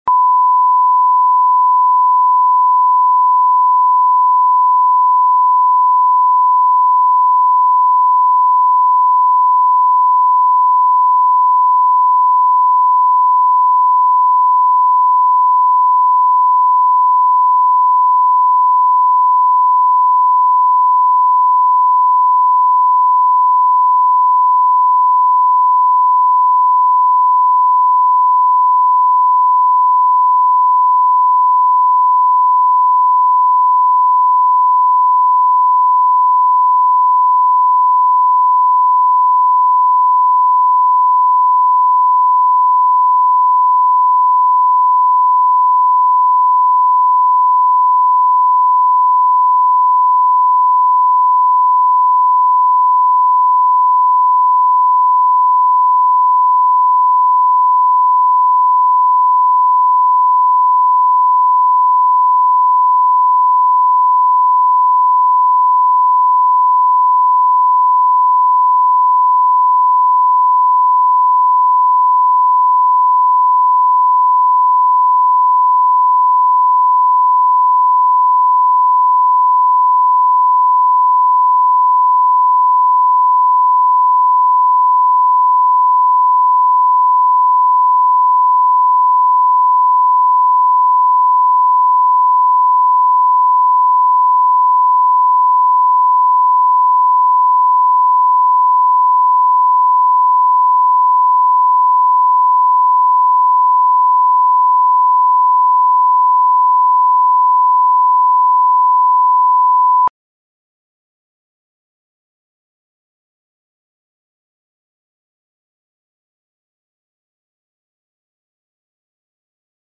Аудиокнига Интервью